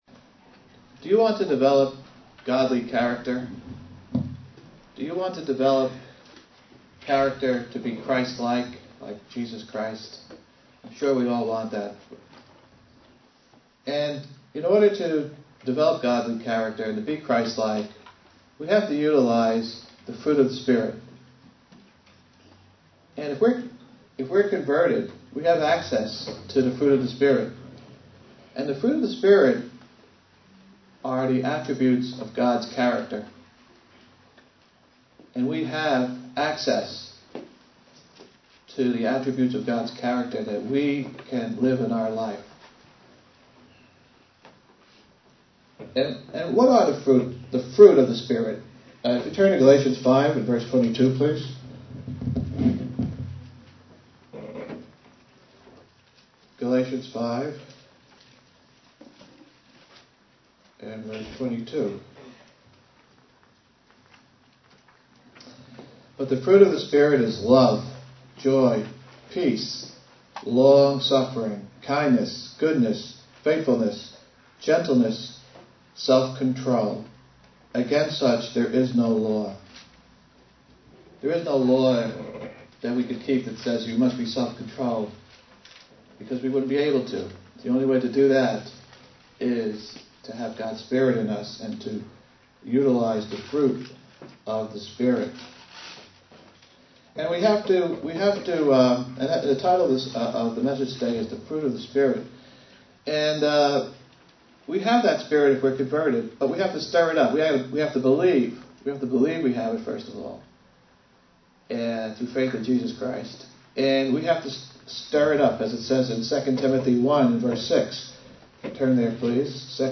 Given in New York City, NY
Print Expounding on Galatians 5:22,23 UCG Sermon Studying the bible?